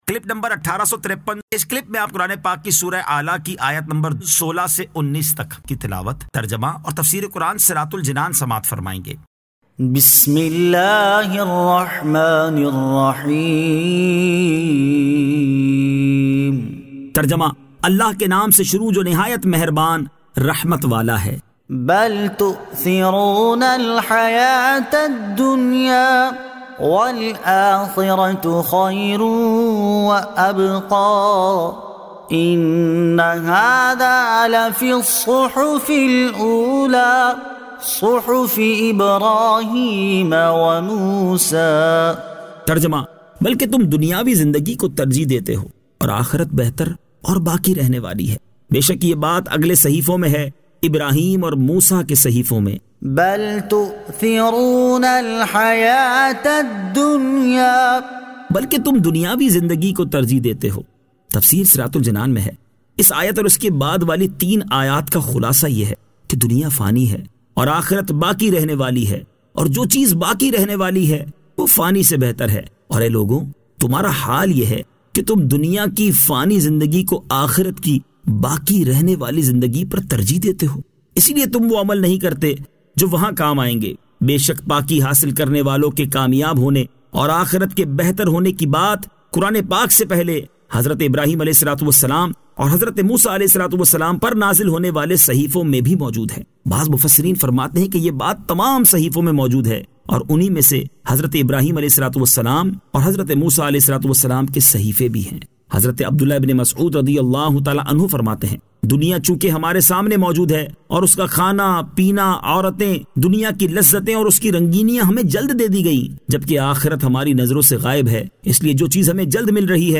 Surah Al-A'la 16 To 19 Tilawat , Tarjama , Tafseer